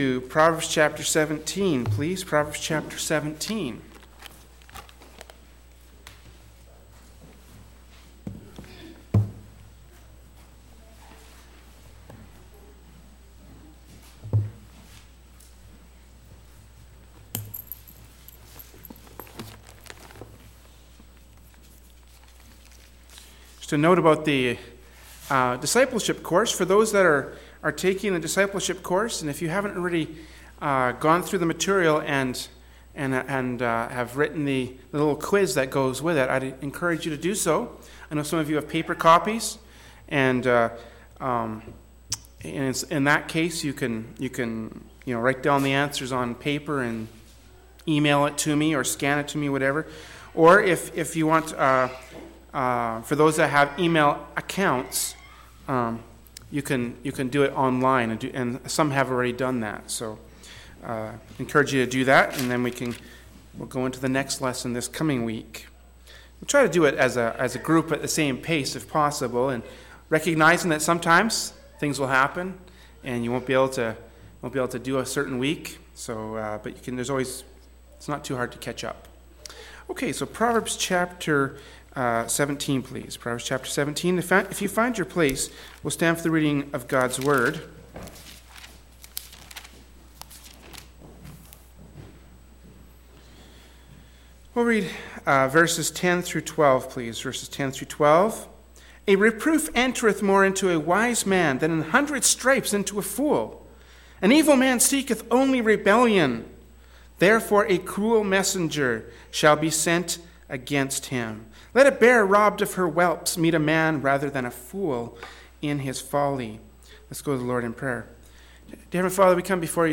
“Proverbs 17:10-12” from Sunday School Service by Berean Baptist Church.